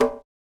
Perc (Russian).wav